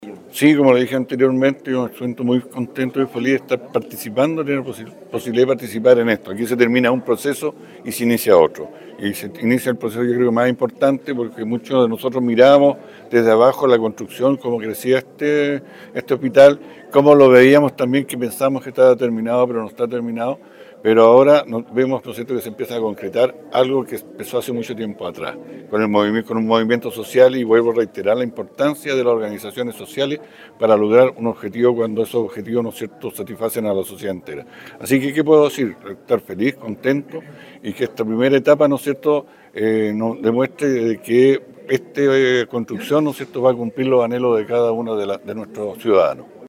En este  mismo sentido, el alcalde de Quellón, Claudio Barudy, indicó:
Claudio-Barudy-Alcalde-de-Quellon.mp3